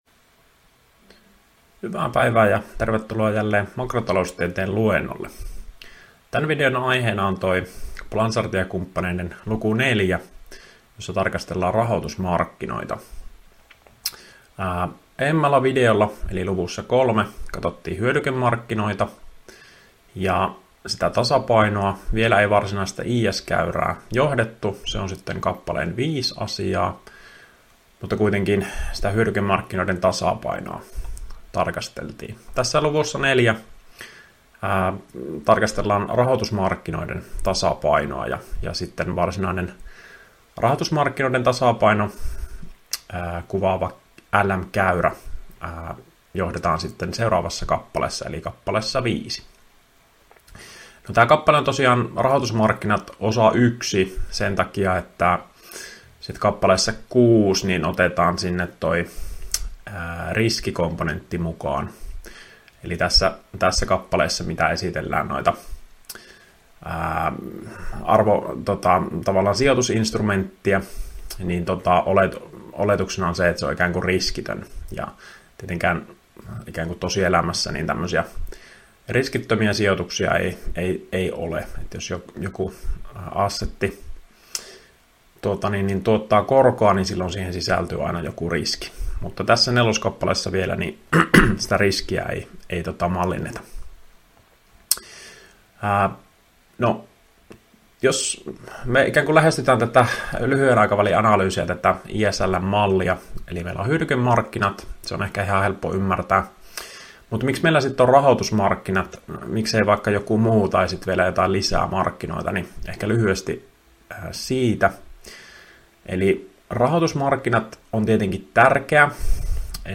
Opintojakson "Makrotaloustiede I" suhdanneosion 3. opetusvideo.